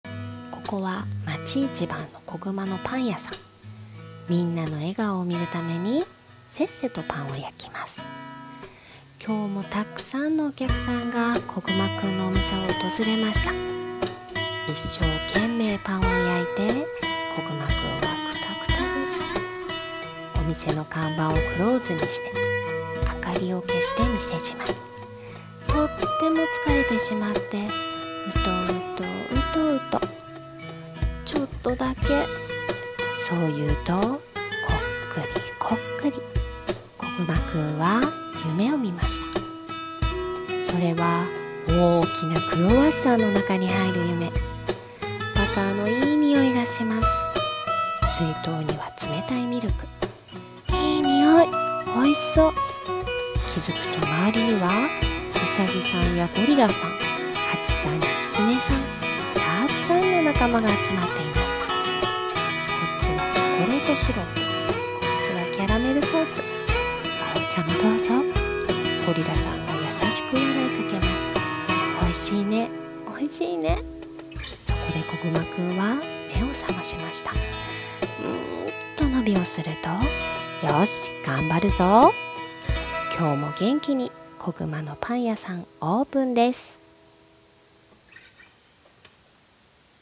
【絵本風声劇】こぐまのパン屋さん。